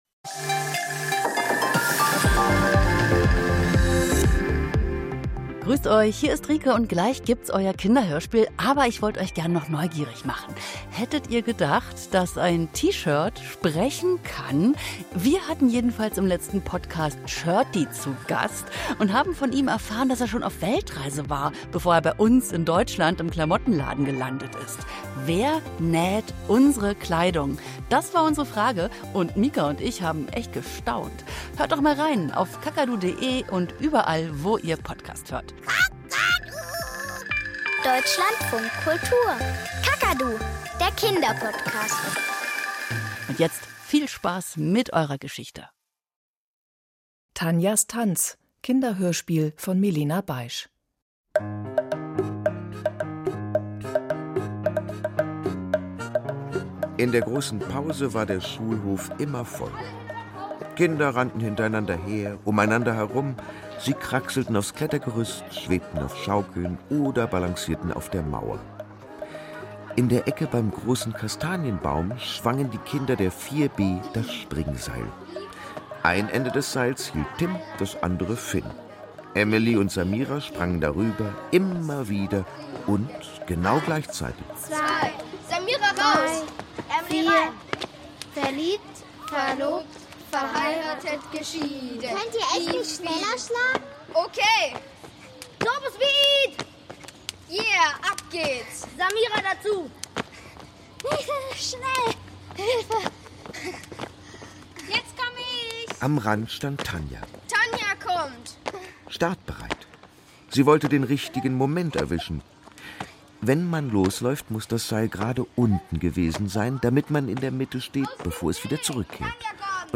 Kinderhörspiel - Tanjas Tanz